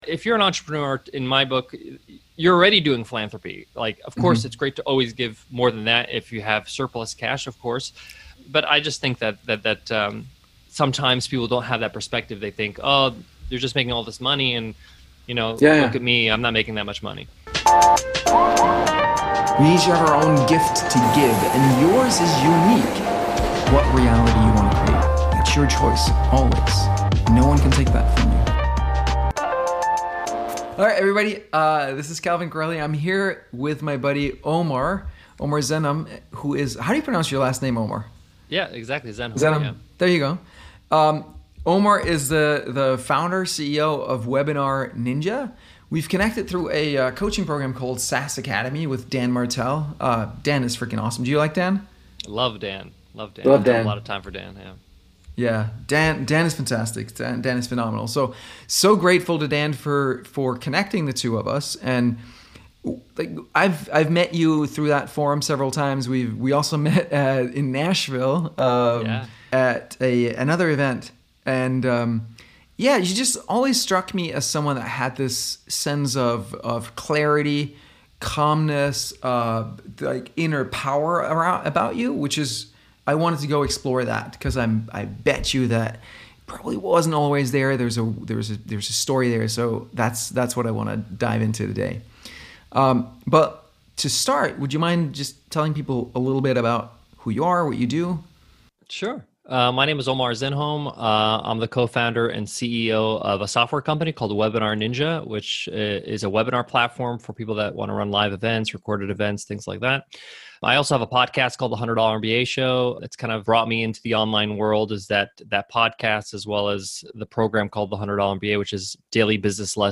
Unleashing Your Inner Fire Interview